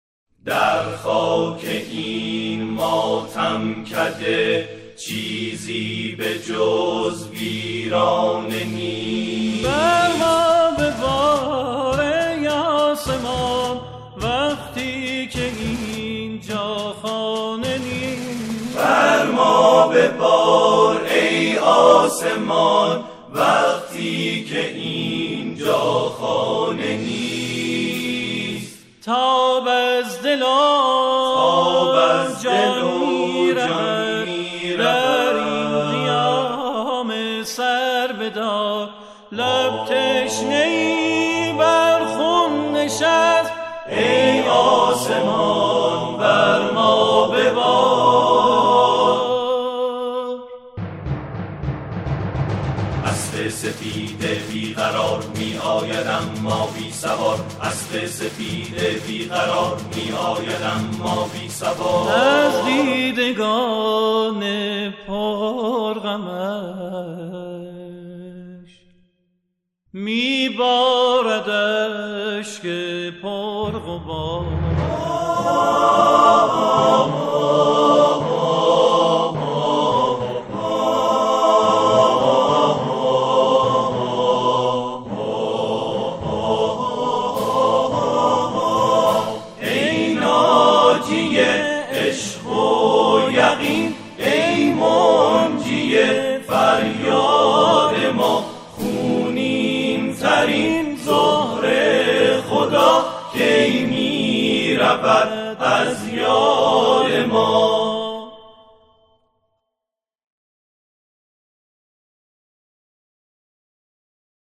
سرودهای امام حسین علیه السلام
گروهی از جمعخوانان